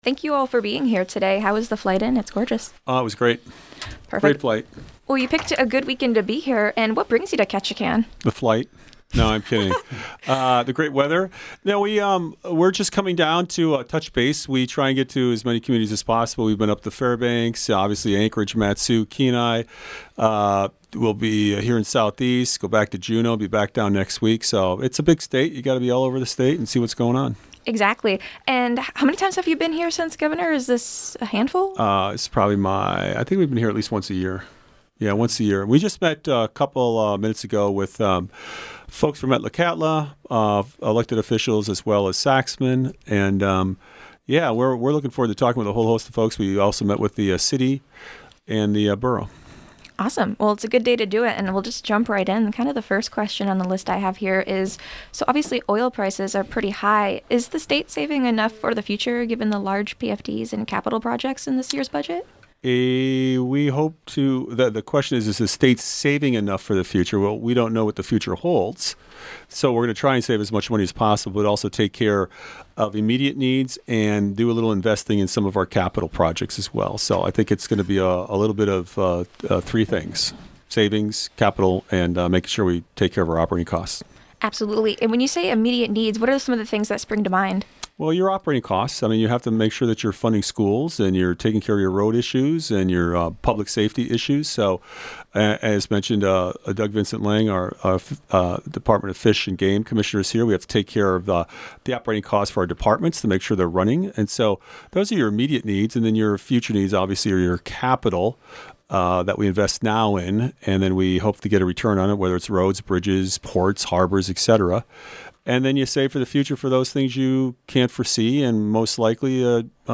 Gov. Mike Dunleavy talks ferries, tribes and other key issues in KRBD interview
Click below for an extended version of the interview.